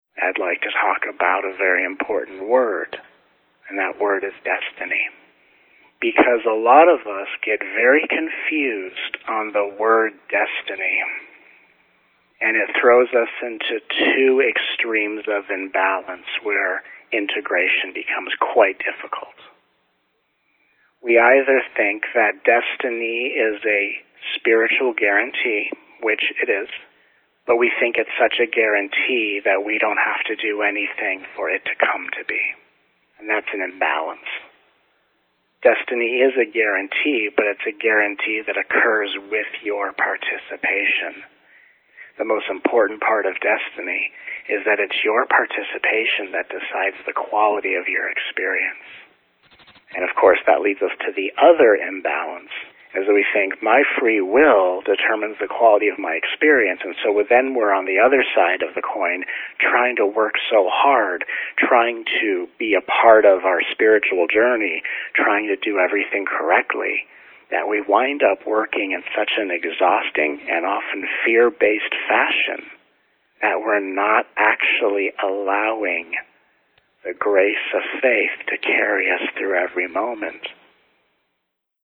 9-Week Telegathering Series (includes 2 calls dedicated to answering life’s biggest questions)